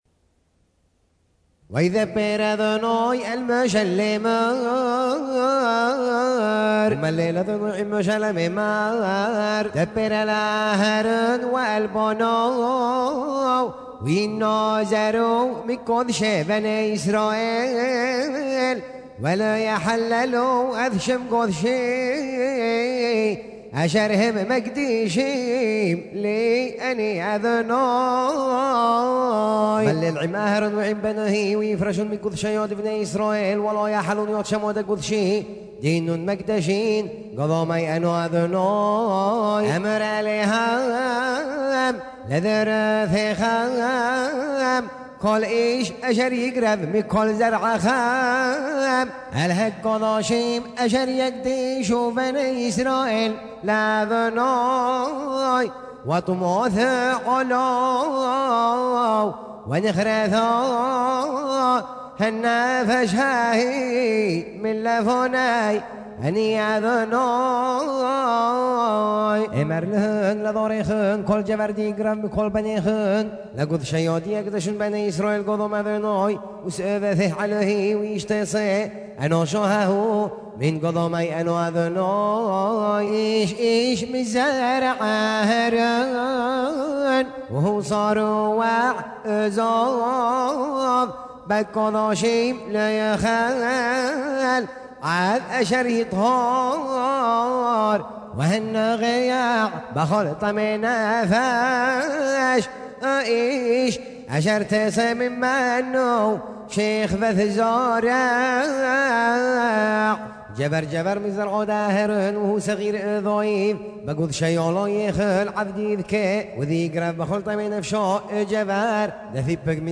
ז'אנר: Blues.